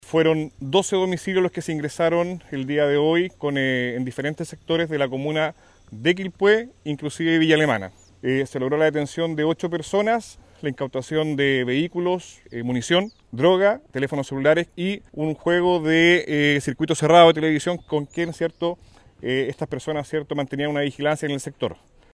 El Coronel, Jorge Guaita, prefecto de Carabineros de Marga Marga, entregó más detalles de los procedimientos.